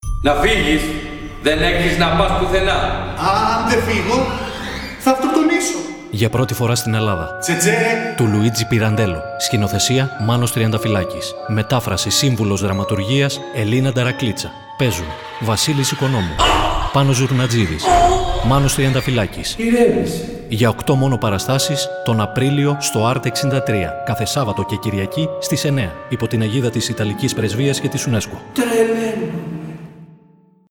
Pirandello-Cecè-Radio-Spot-1.mp3